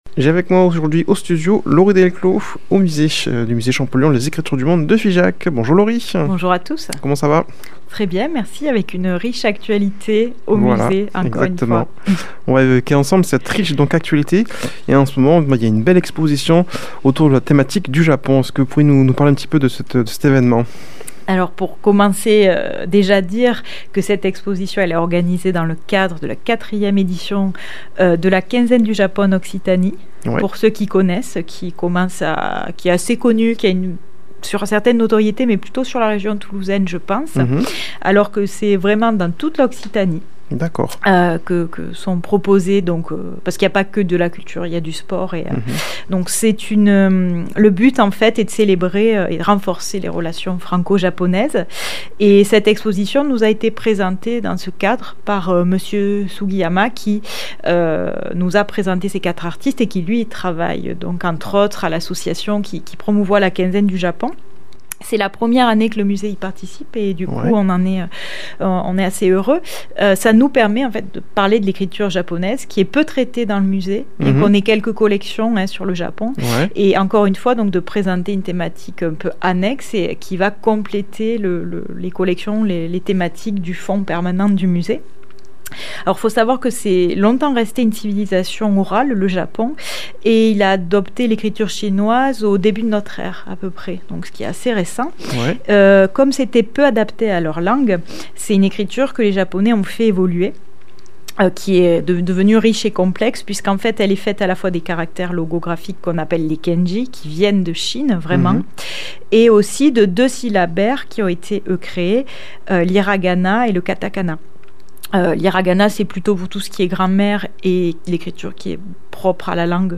Présentateur